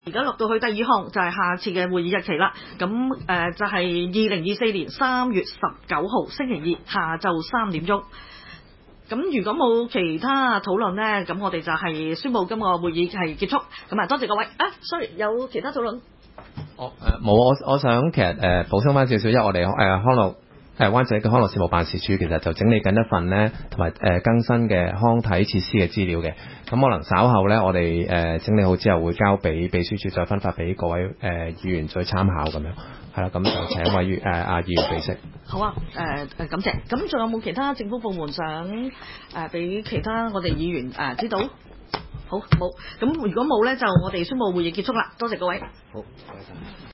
湾仔区议会 - 委员会会议的录音记录
湾仔民政事务处区议会会议室